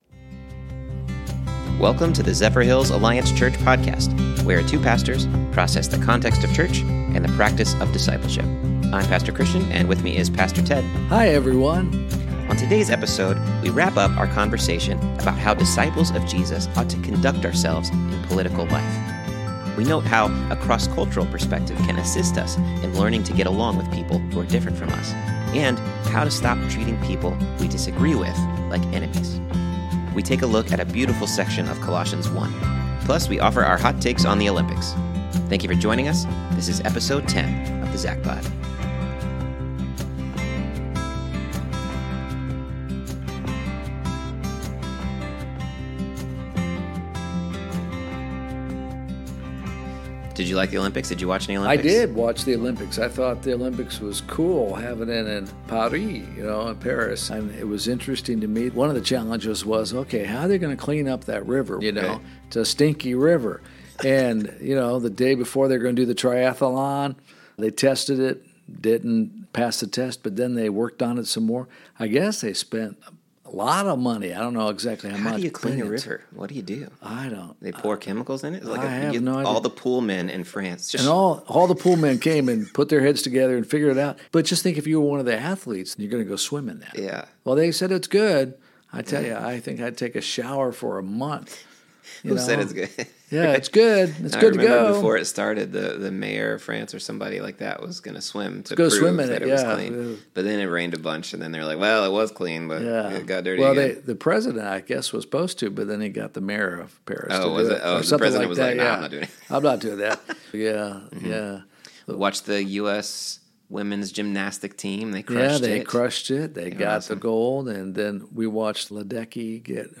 This is the Zephyrhills Alliance Church Podcast, where two pastors discuss the context of church and the practice of discipleship.